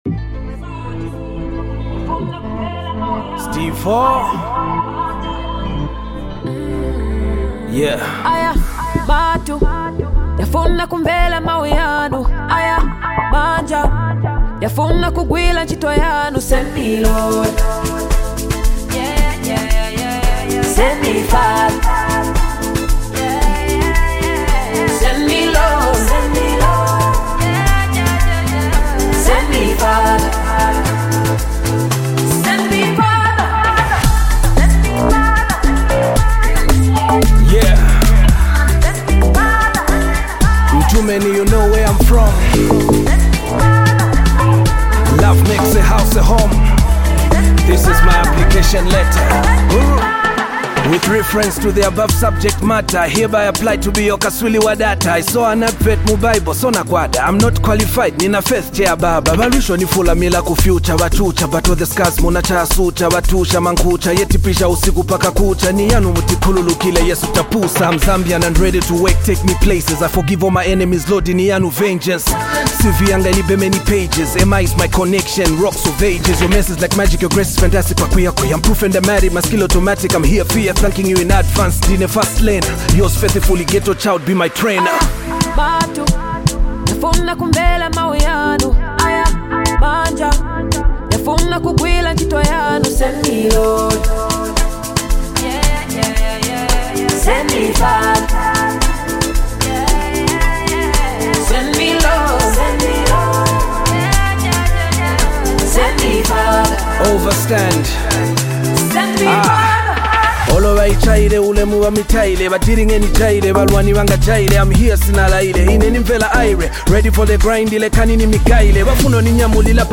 takes on a spiritual tone
soulful vocals
introspective verses